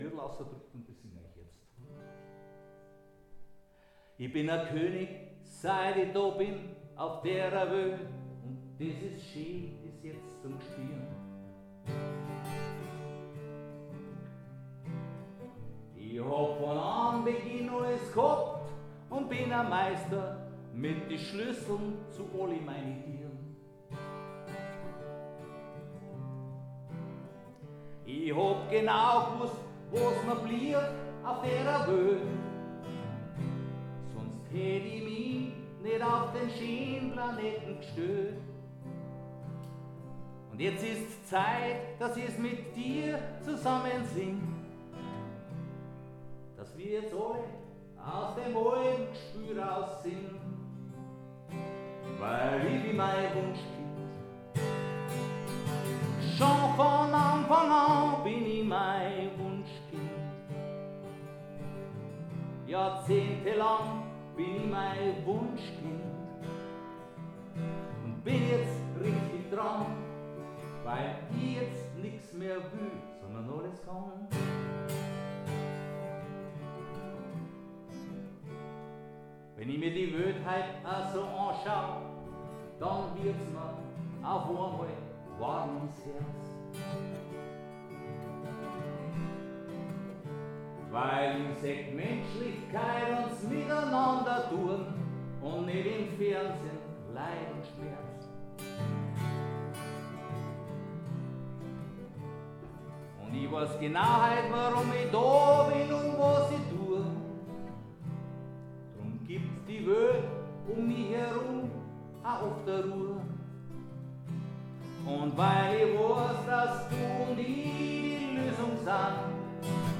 Hier noch ein paar Klangbeiträge vom gestrigen Mitschnitt 😀 ENJOY IT